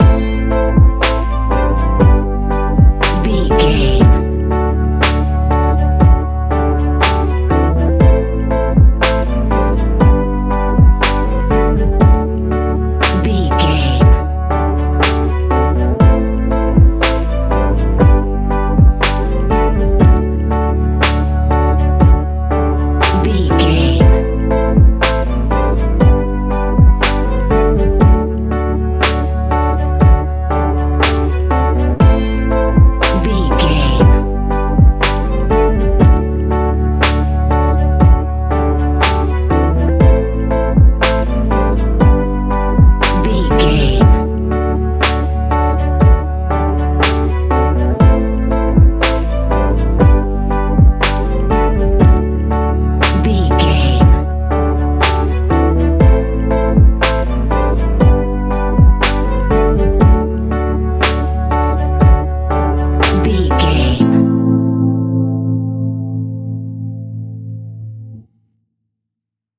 Ionian/Major
D♭
chilled
laid back
sparse
new age
chilled electronica
ambient
atmospheric
morphing